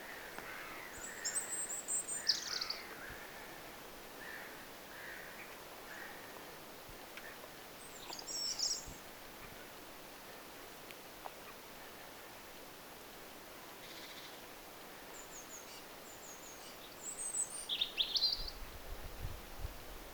tuollaiset punarinnan säkeet
tuollaiset_punarinnan_sakeet.mp3